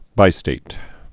(bīstāt)